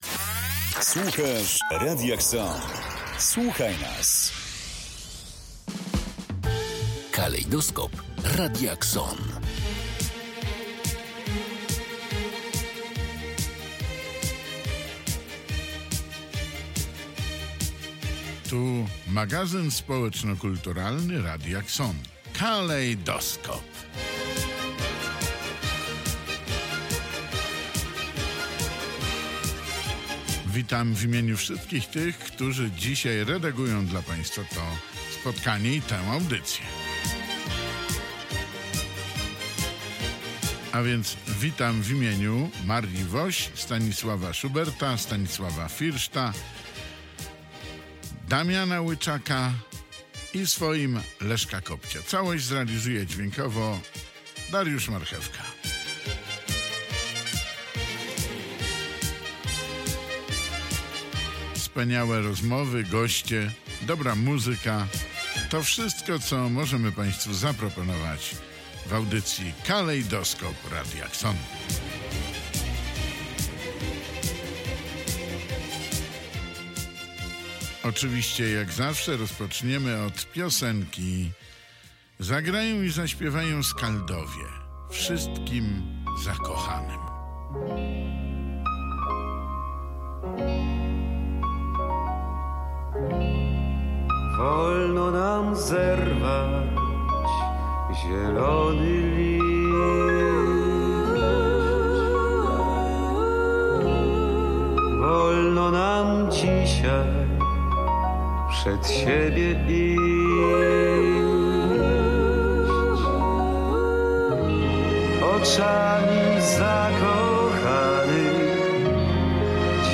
Audycję otwiera piosenka **"Karolin"**.